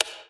light-off.wav